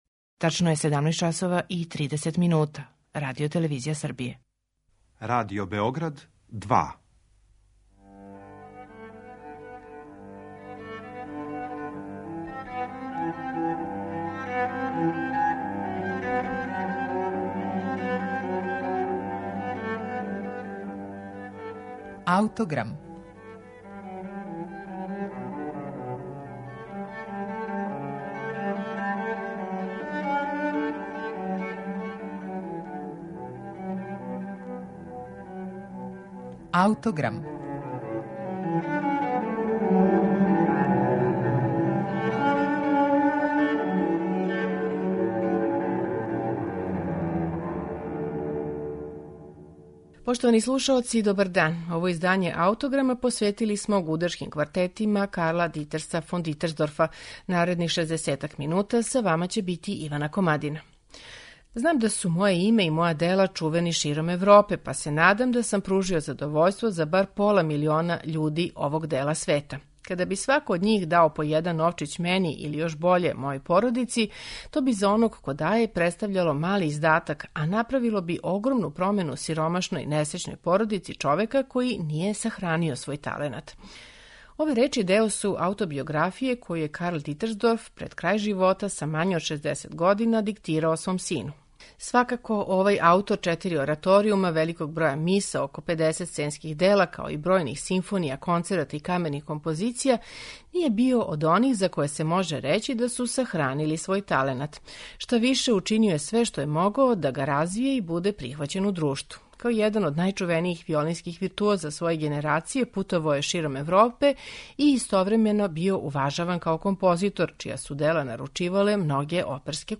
Оно што је извесно јесте да је аутор овде користио различите облике тонске текстуре, врло суптилне динамичке промене, а мелодијске деонице најчешће водио у форми дијалога.
У данашњем Аутограму представићемо Дитерсдорфове гудачке кварете број 3, 4 и 5. Слушаћете их у интерпретацији квартета „Шарон".